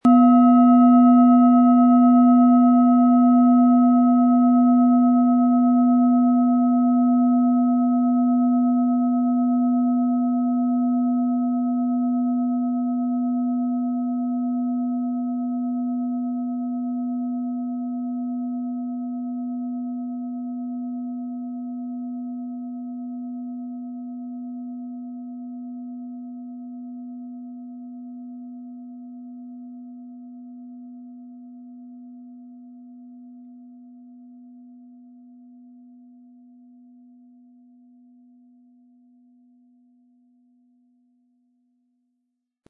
Platonisches Jahr
• Tiefster Ton: Mond
Mit einem sanften Anspiel "zaubern" Sie aus der Platonisches Jahr mit dem beigelegten Klöppel harmonische Töne.
MaterialBronze